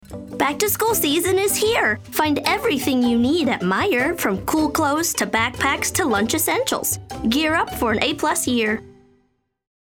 Female
My voice is youthful, engaging, and upbeat, with a clear and lively tone. It has a warm, approachable quality that feels friendly and conversational.
Television Spots
Fun , Energetic , Informative